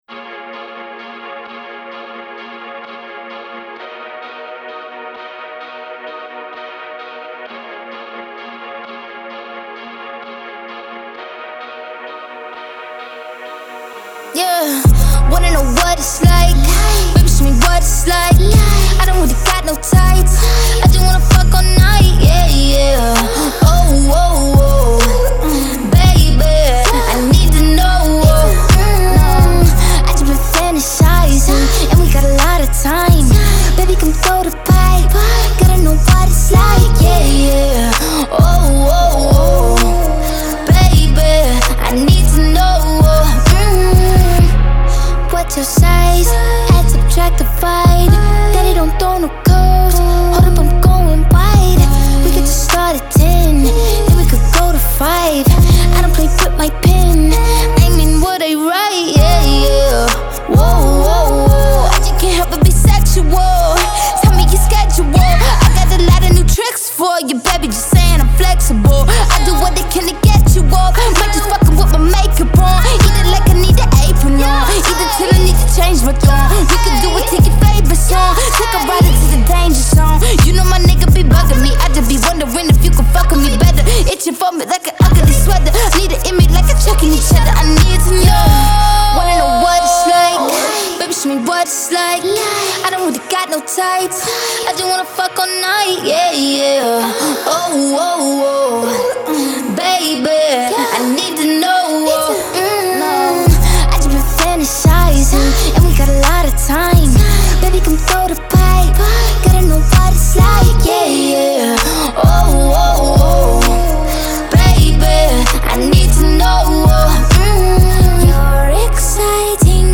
Genre : Pop, R&B